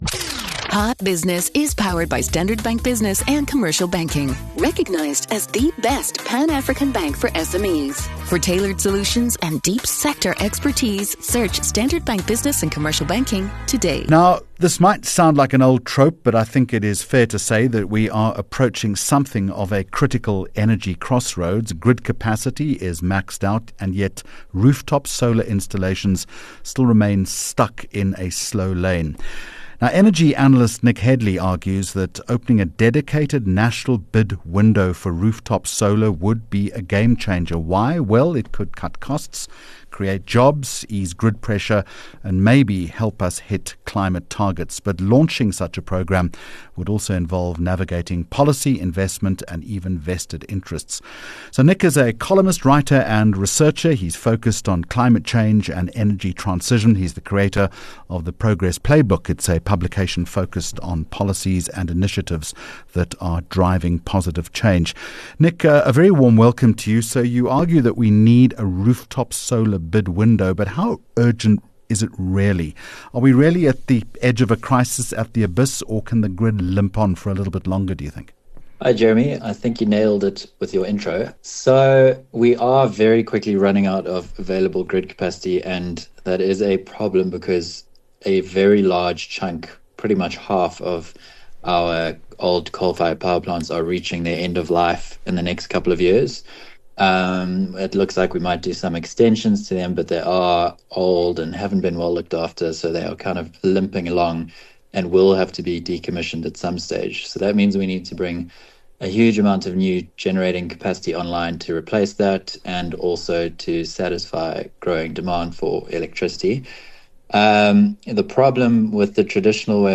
15 Jul Hot Business Interview